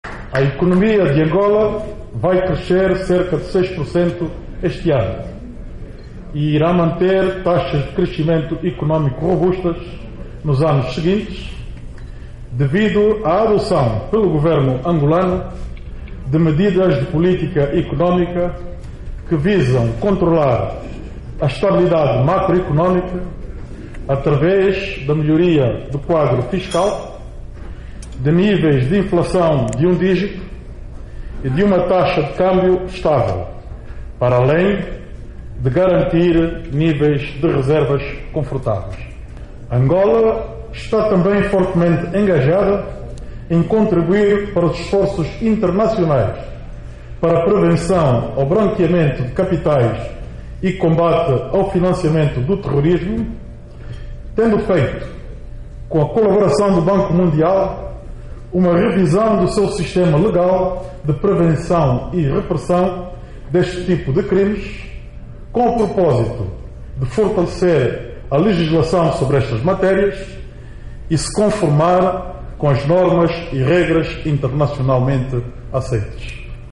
Vice-Presidente angolano disse que economia angolana vai crescer este ano 6%. No evento promovido pela Câmara do Comércio EUA-Angola, foi também assinado um Memorando com o Eximbank
Vice presidente fala sobre economia angolana - 1:16